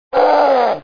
Doom Guy Death Scream